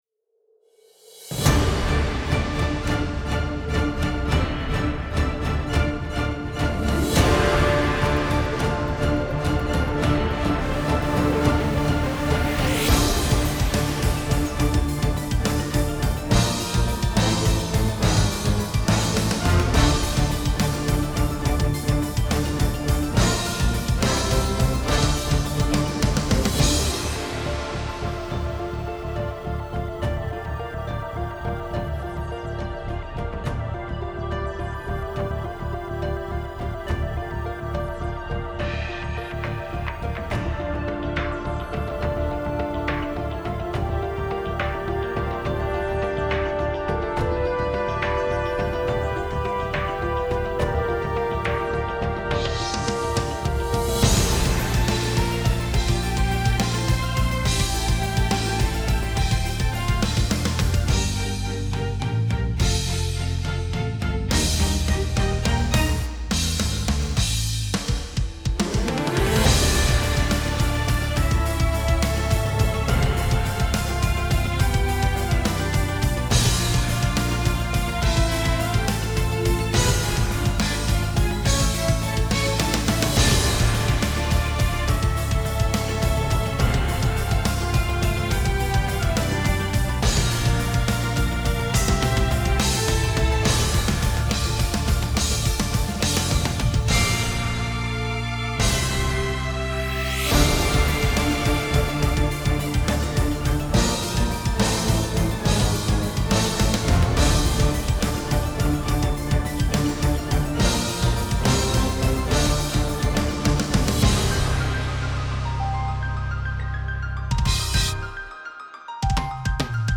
guitarless tracks